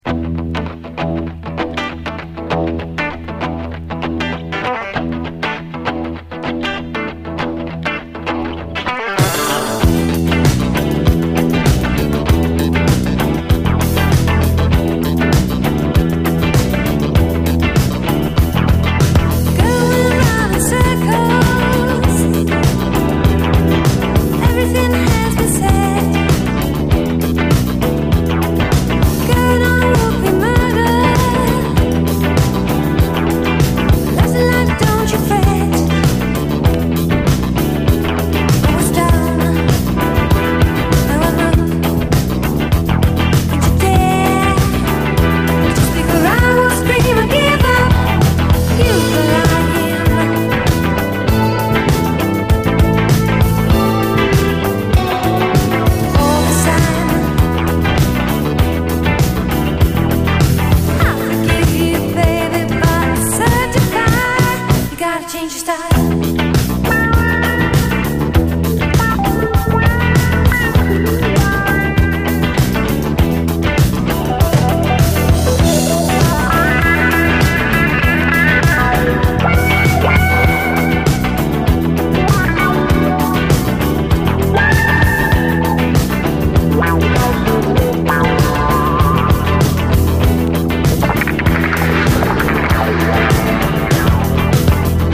タダのストリングス・グループではナシ！
幽玄ジャズ・ファンク
ヒンヤリとサイケデリックでブッ飛んだアレンジがヤバい、マッド・ファンク傑作！シタール入りでさらにイカれたジャズ・ファンク